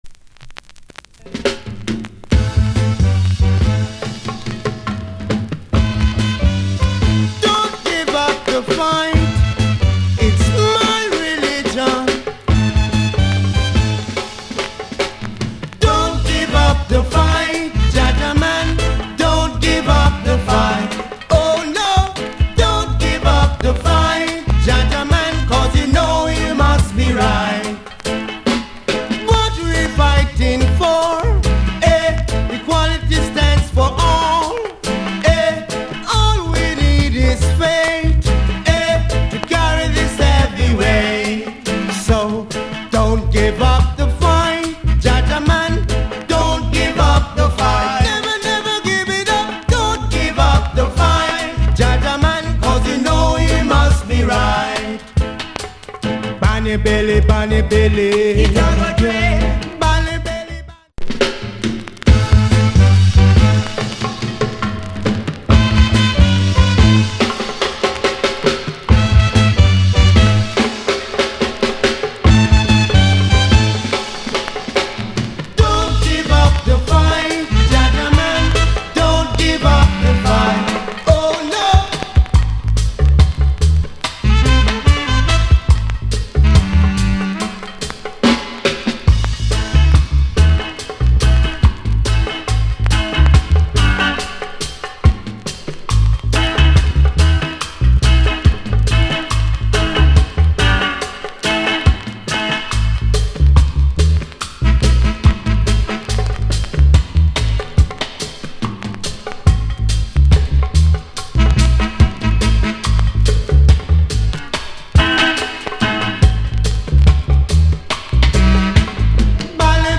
Male Vocal Group Vocal Condition VG(LD